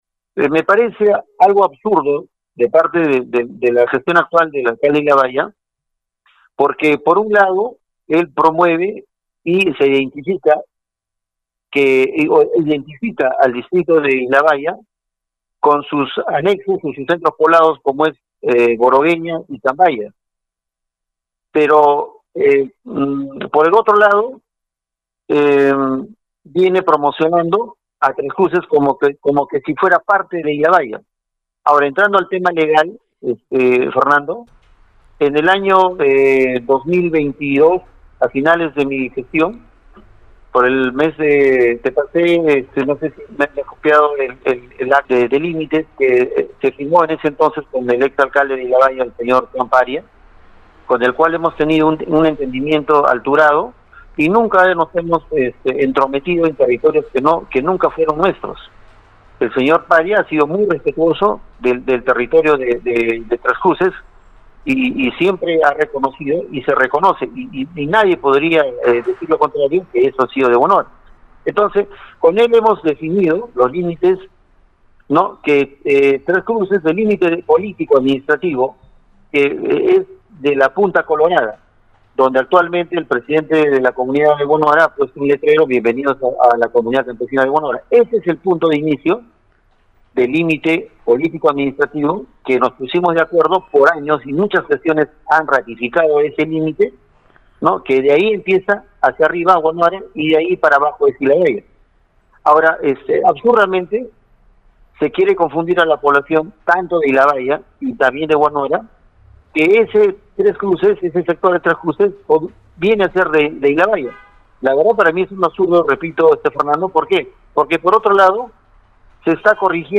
(Audio: Gary Calizaya, exalcalde de Huanuara)
gary-calizaya-sobre-huanuara.mp3